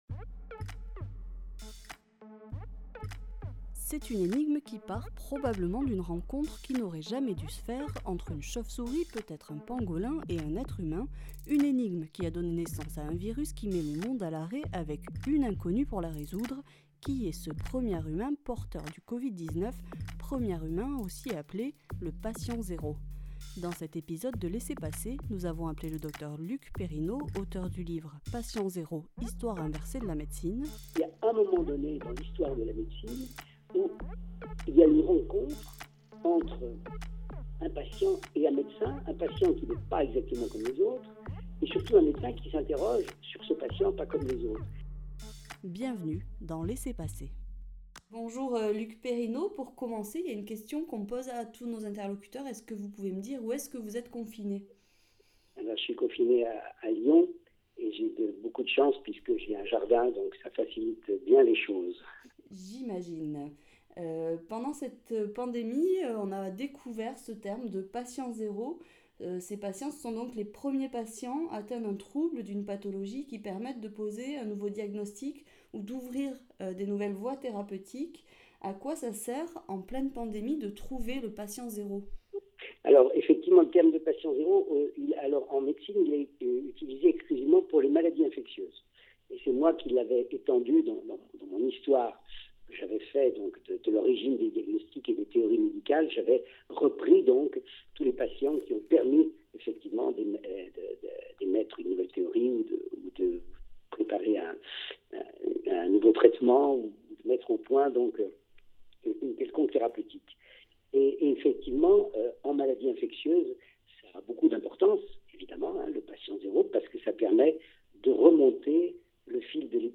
L’histoire de la médecine est ponctuée de patients zéro, qui, en étant les premiers à développer une pathologie, ont permis de faire avancer la recherche. Comment les retrouve-t-on, pourquoi les identifier et quelles dérives cela peut entraîner ? (interview audio)